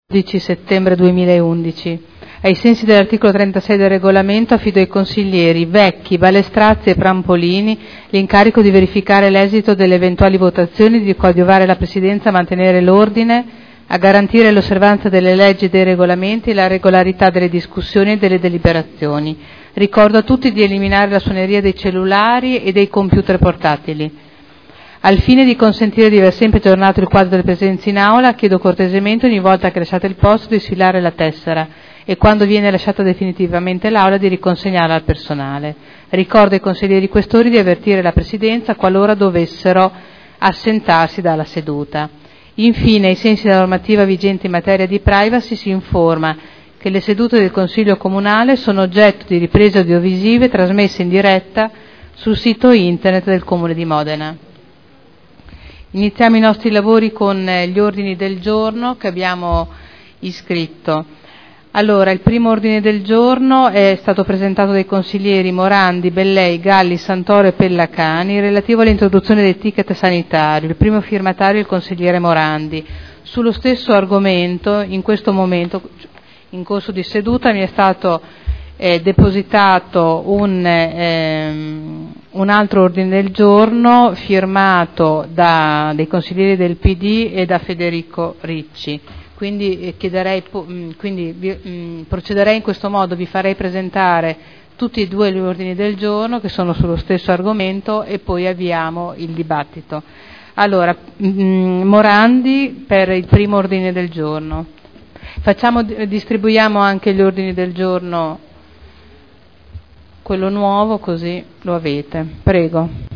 Seduta del 12/09/2011. Il Presidente Caterina Liotti apre i lavori del Consiglio.